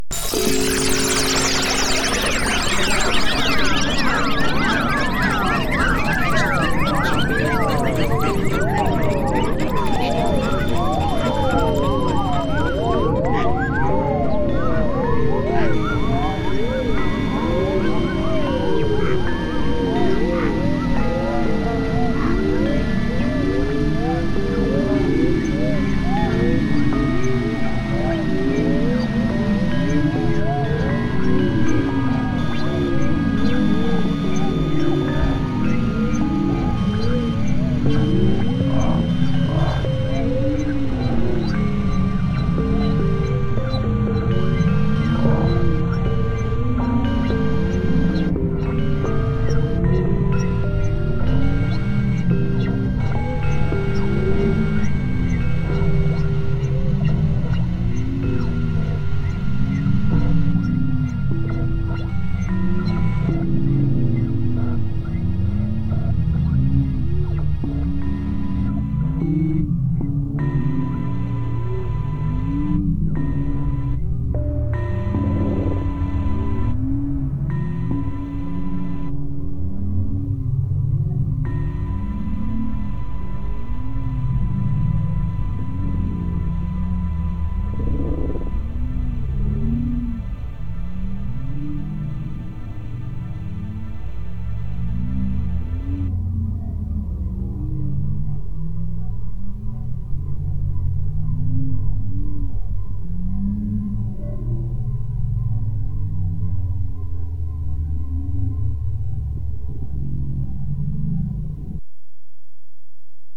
SERIALISTIC MICROTONALITY
IN ANY CHROMATIC STYLE SCALE UP TO 1000EDO
SERIALISM, SPATIALISATION, DODECOPHANY, DISSONANT SCHOOLS